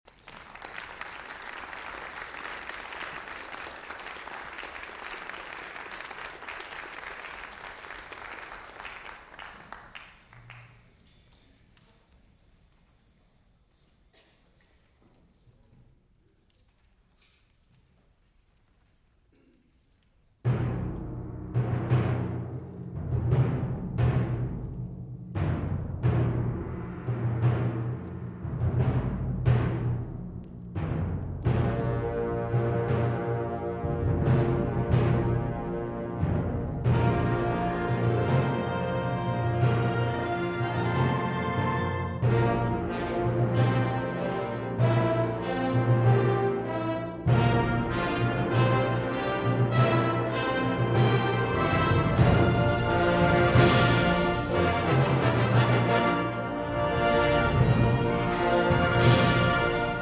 Trumpets